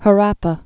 (hə-răpə)